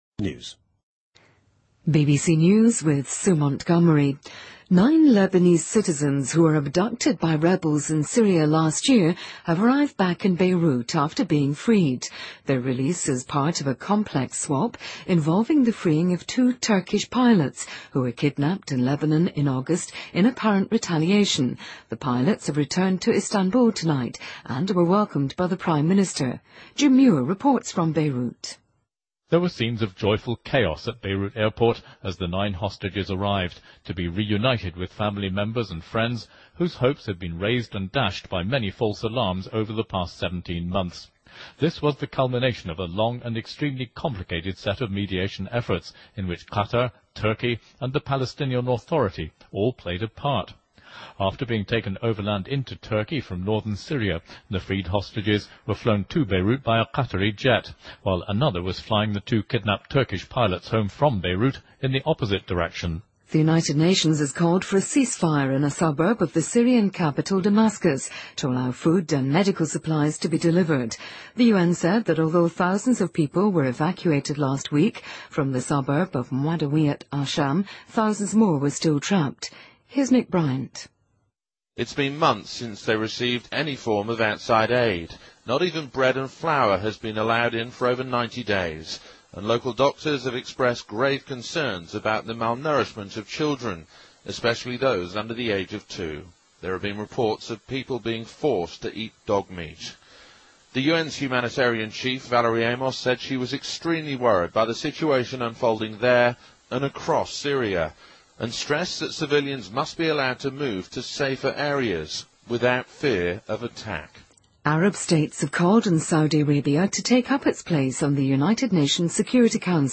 Date:2013-10-20Source:BBC Editor:BBC News